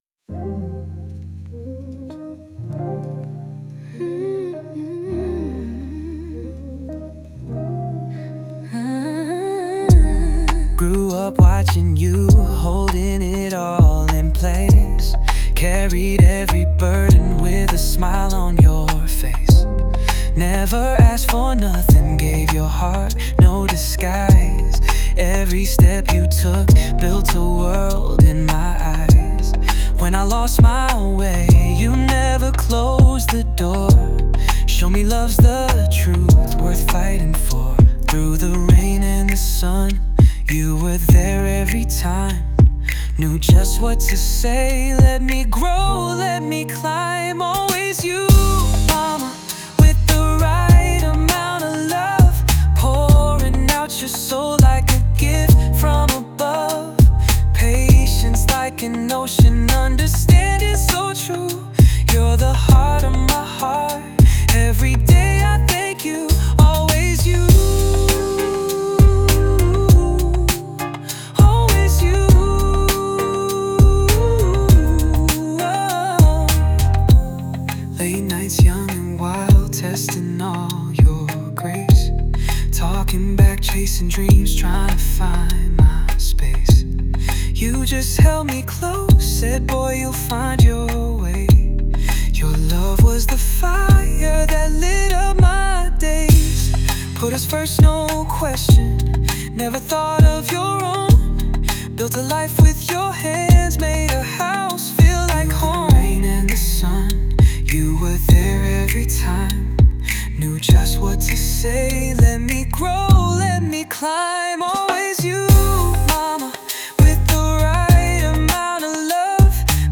Pop, R&B